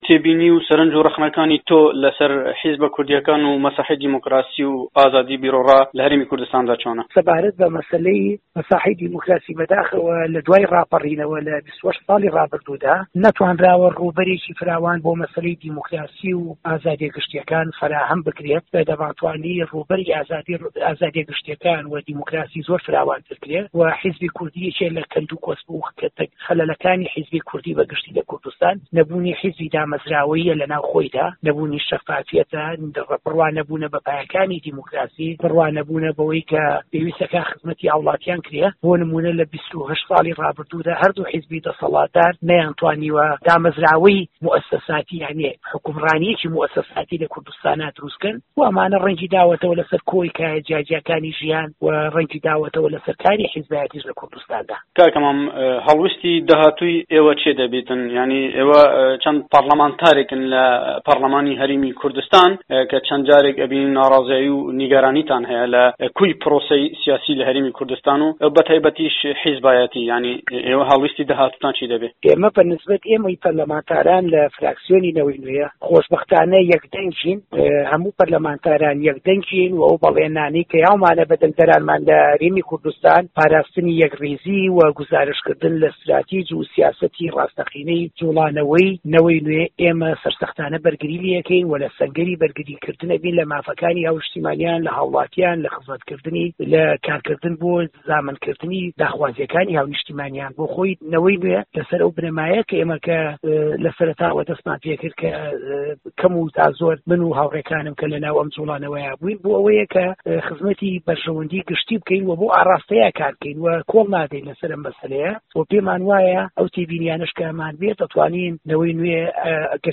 وتووێژ لەگەڵ مەم بورهان قانع